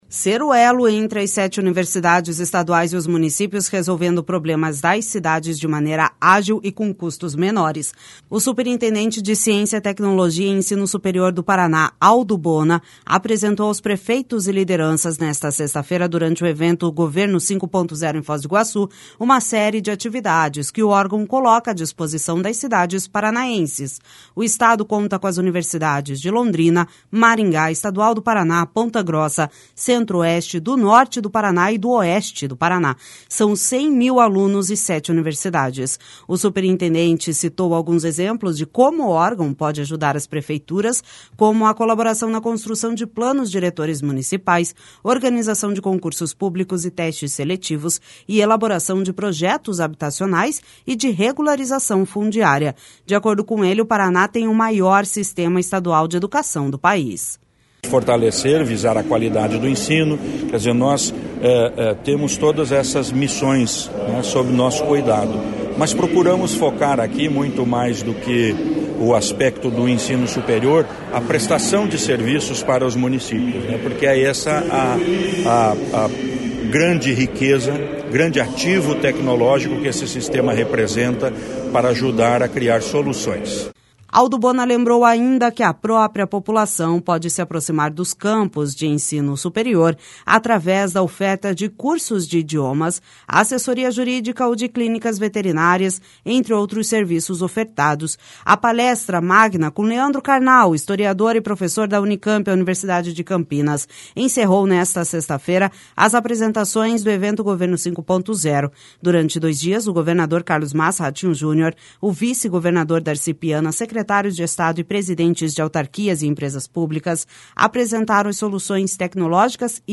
O superintendente de Ciência, Tecnologia e Ensino Superior do Paraná, Aldo Bona, apresentou aos prefeitos e lideranças, nesta sexta-feira, durante o evento Governo 5.0, em Foz do Iguaçu, uma série de atividades que o órgão coloca à disposição das cidades paranaenses.